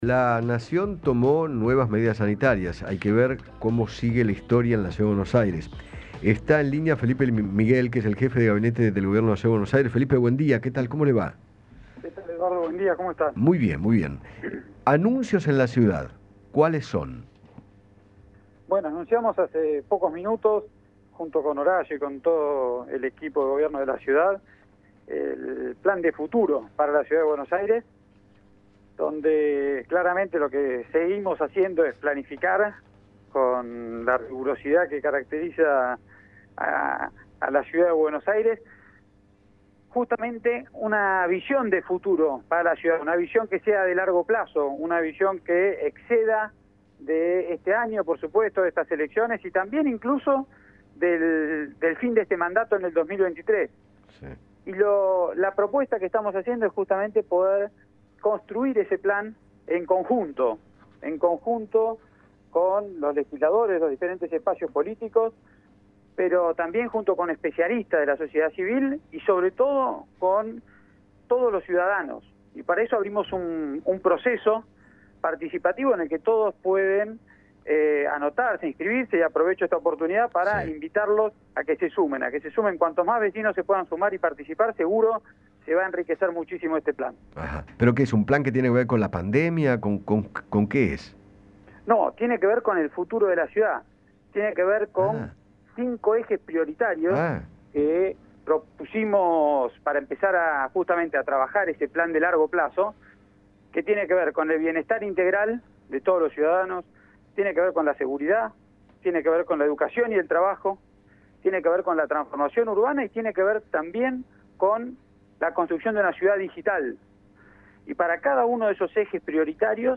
Felipe Miguel, jefe de gabinete de la Ciudad de Buenos Aires, habló con Eduardo Feinmann acerca de las flexibilizaciones que anunció el Gobierno en las restricciones sanitarias  y aseguró que “desde la Ciudad vamos a evaluar las implicancias de los anuncios”.